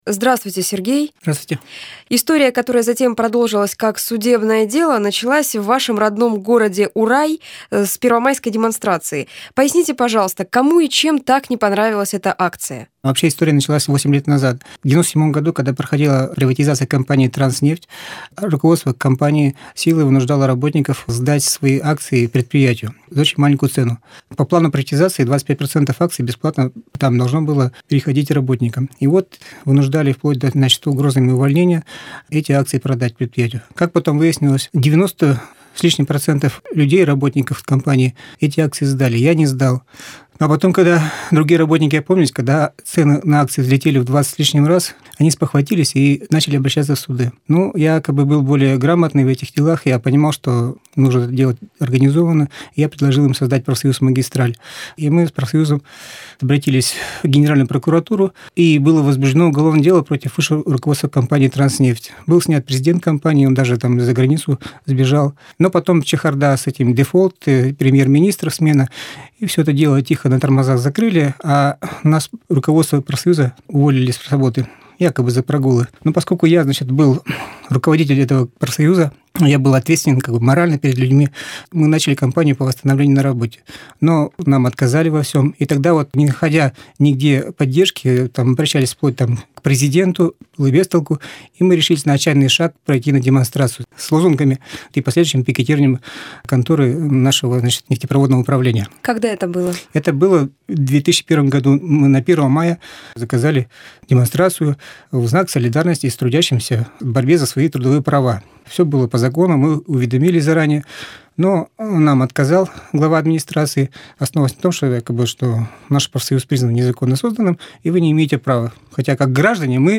Вы здесь: Главная / Библиотека / Интервью сутяжников / Интервью
Библиотека